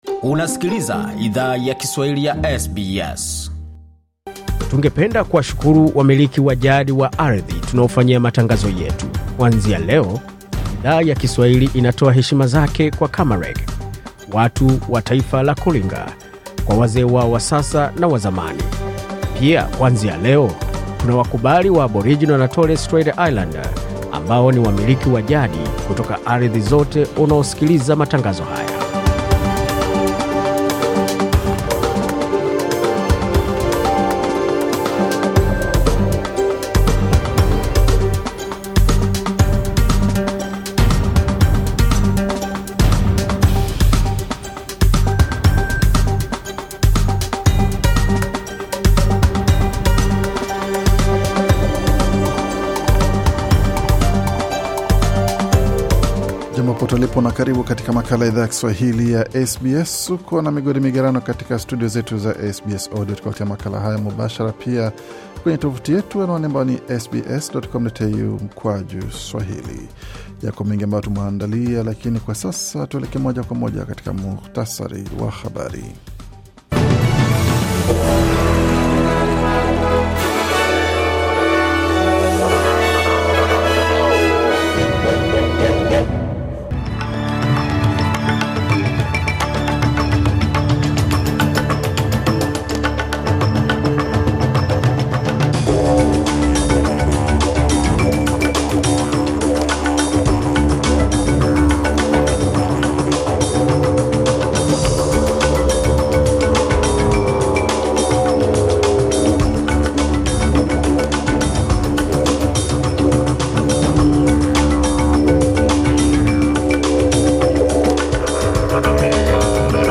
Taarifa ya Habari 8 Aprili 2025